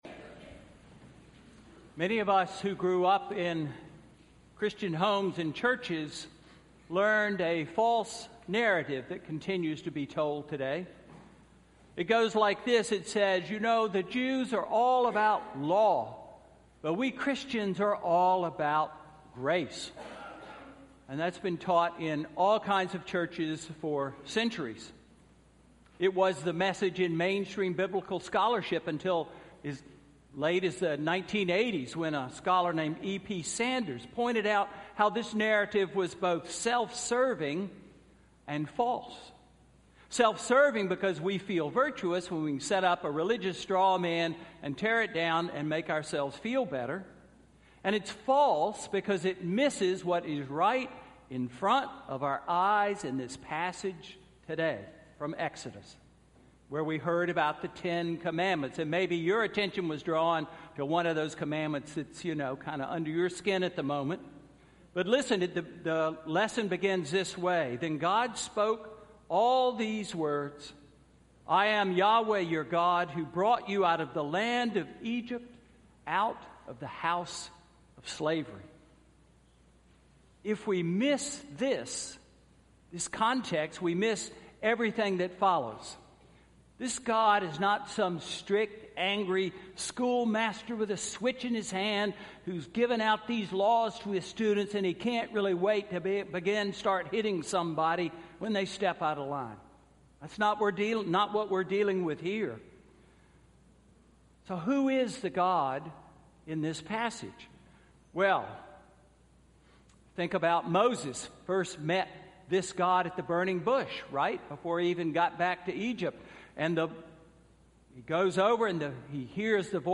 Sermon–March 4, 2016